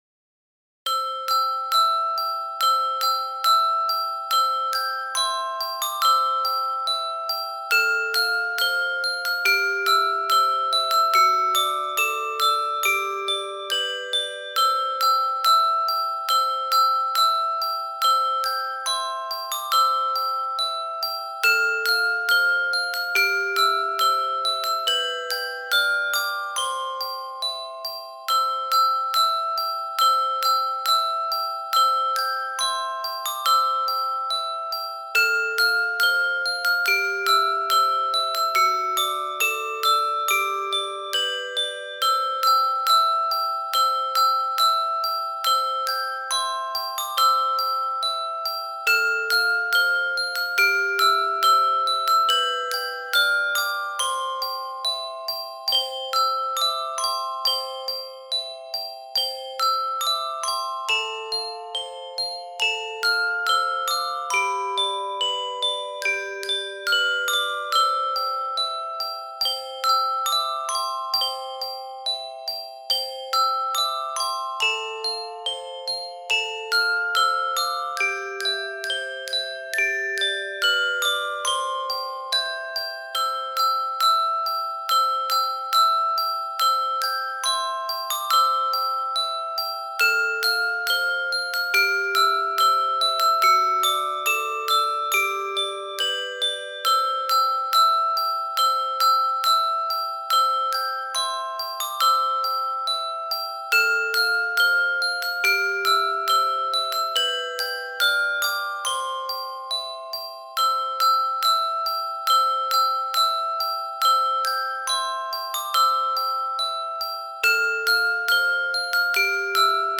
Jingle Bells sesli tiyatrosu ile eğlenceli ve eğitici bir maceraya çıkın.